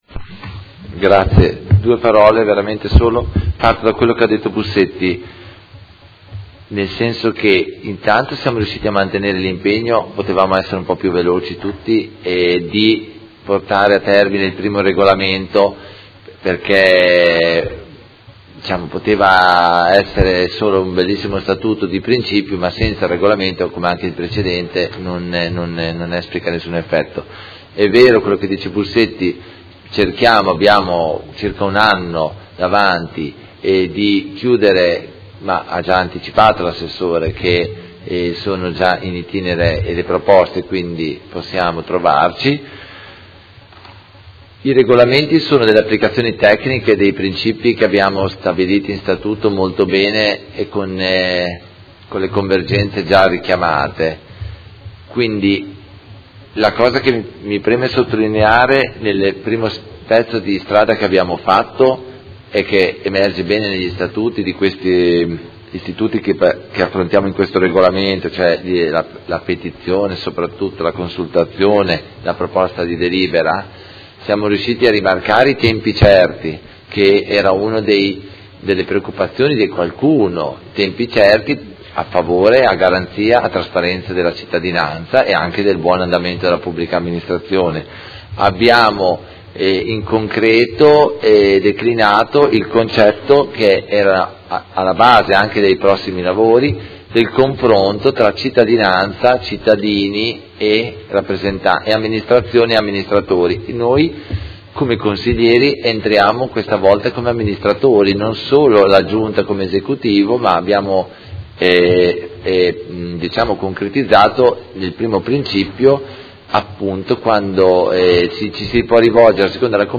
Seduta del 15/03/2018. Dibattito su proposta di deliberazione: Revisione del Regolamento sugli istituti di partecipazione dei cittadini del Comune di Modena